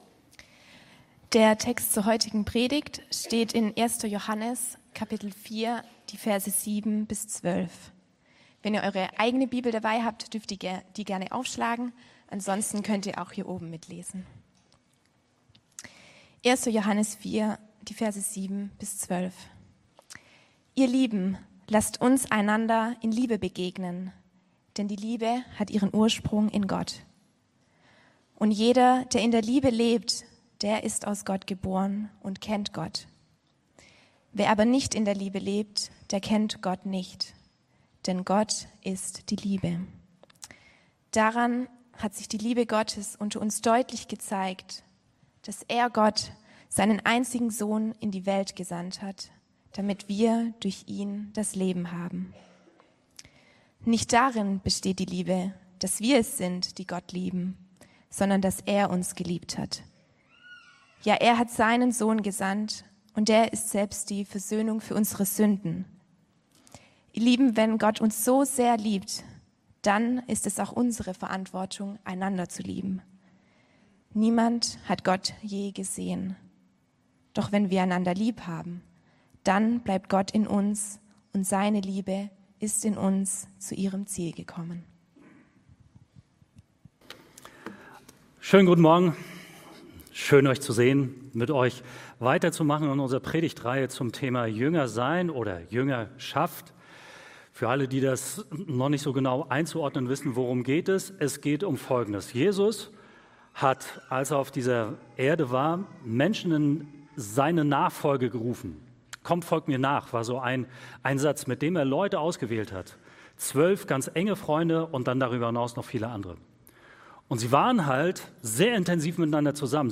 Predigten – Er-lebt. Eine Kirche für Landau